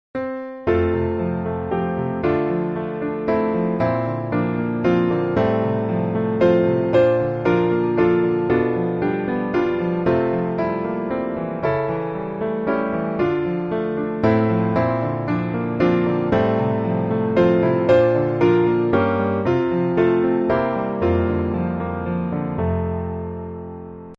Piano Hymns
F Major